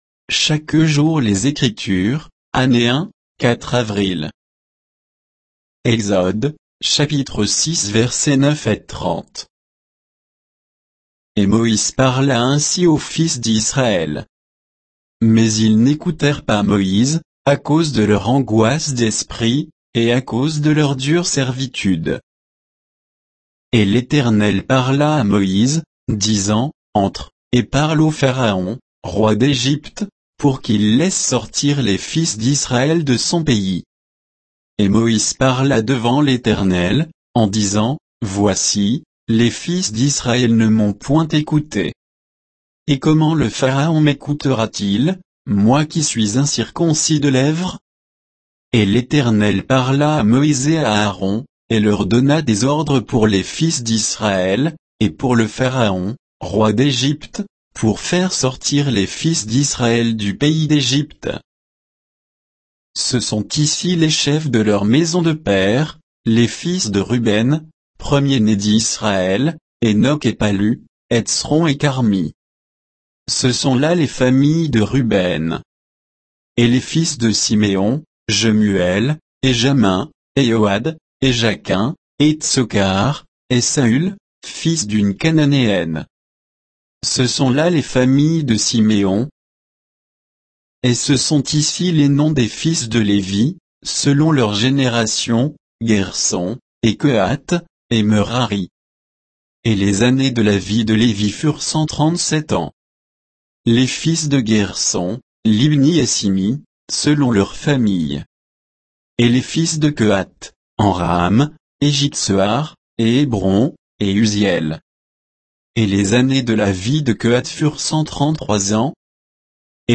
Méditation quoditienne de Chaque jour les Écritures sur Exode 6, 9 à 30